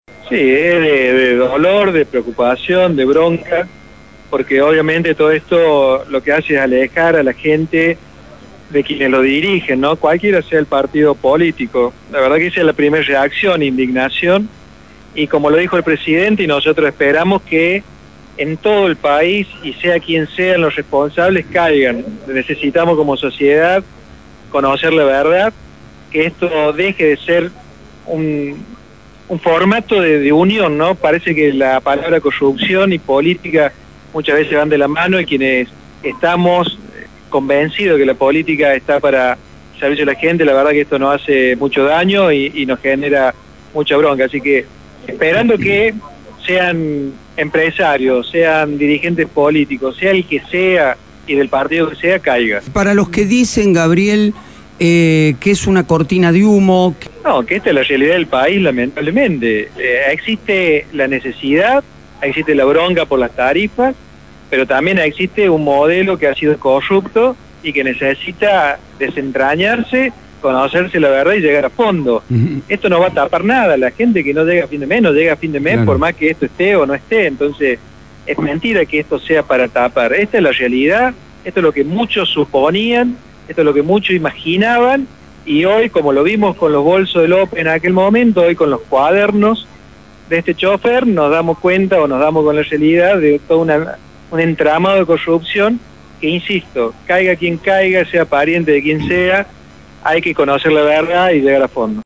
El Diputado Nacional lo expresó en el marco de la inauguración de la sede legislativas de Cambiemos en nuestra ciudad, acompañado de los dirigentes más encumbrados del partido que encabeza el presidente Mauricio Macri. Habló del escándalo de presunta corrupción que originó más de 20 detenciones y llamados a indagatoria.